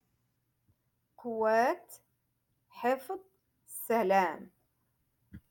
Moroccan Dialect- Rotation Five-Lesson sixty Three